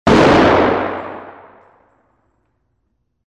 Звуки ружья
Выстрел из помпового дробовика